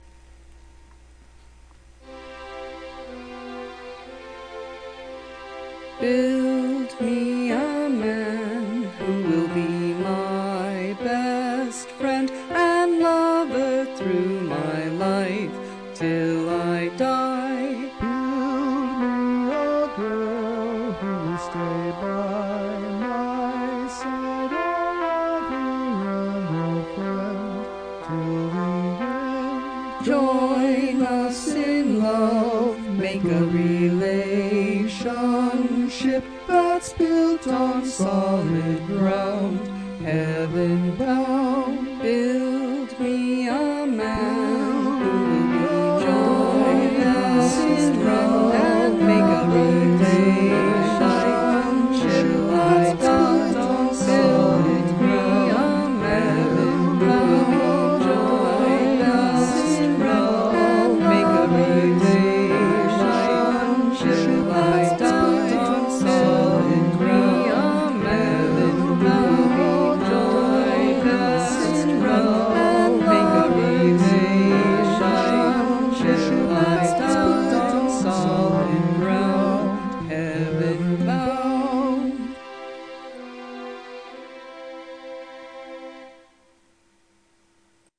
Must include a round.